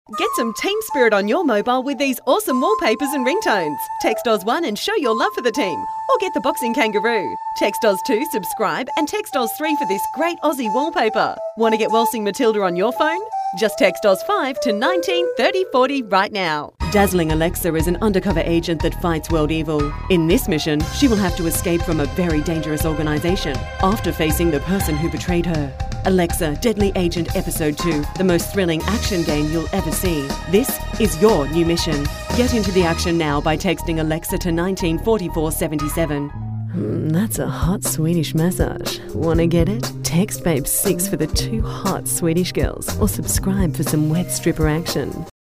Professionelle Sprecher und Sprecherinnen
Englisch (AUS)
Weiblich